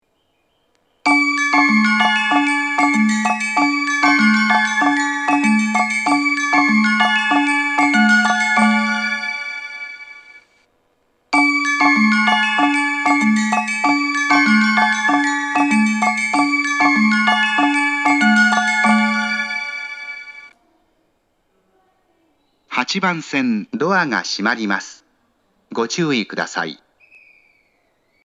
発車メロディー
1.9コーラスです。